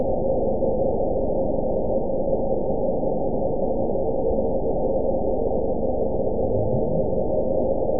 event 912067 date 03/17/22 time 14:49:34 GMT (3 years, 2 months ago) score 9.11 location TSS-AB01 detected by nrw target species NRW annotations +NRW Spectrogram: Frequency (kHz) vs. Time (s) audio not available .wav